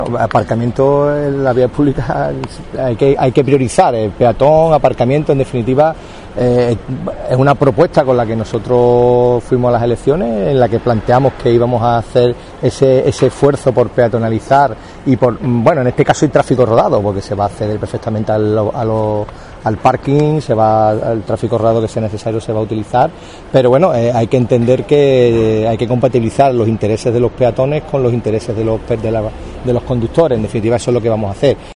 Pepe Fernández primer teniente de alcalde de Régimen Interior, Recursos Humanos y Modernización Digital